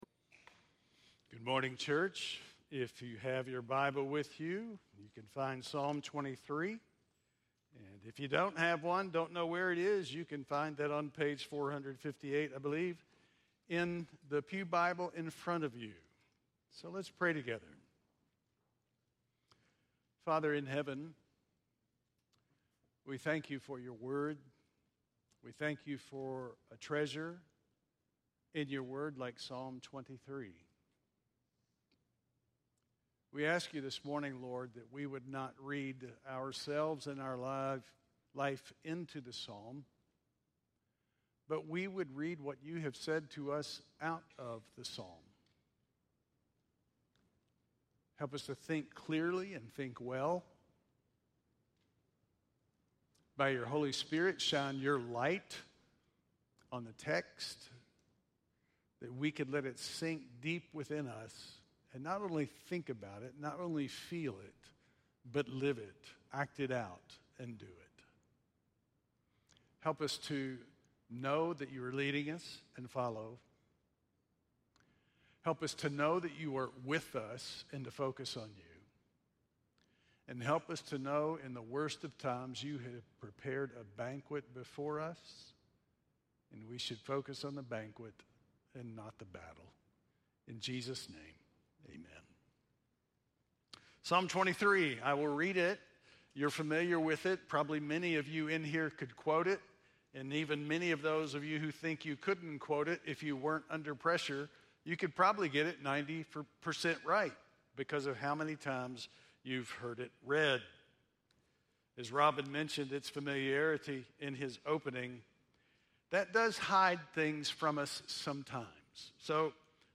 7.21-sermon.mp3